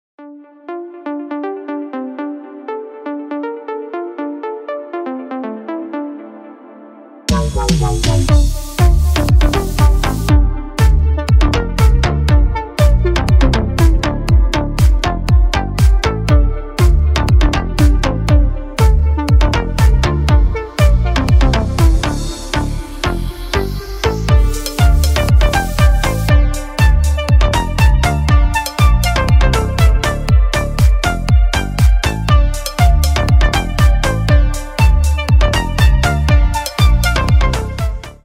Поп Музыка
клубные # без слов